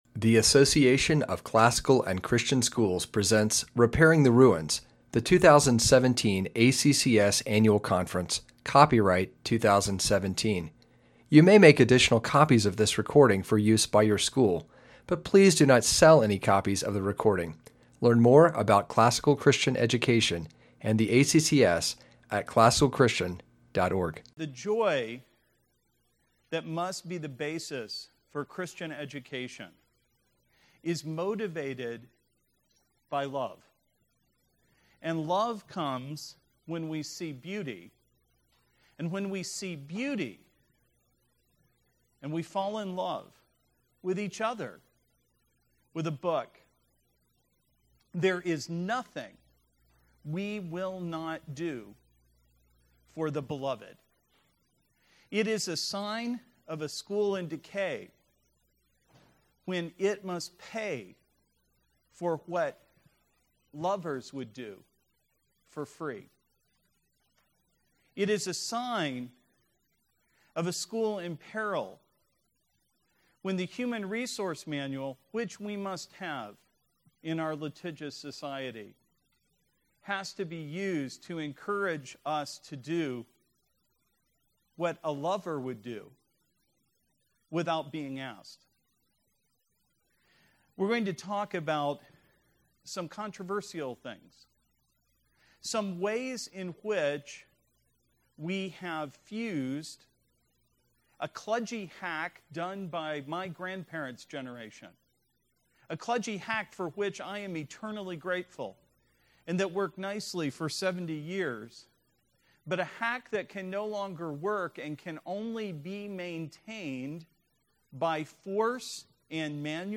2017 Plenary Talk | 0:59:00 | All Grade Levels, Culture & Faith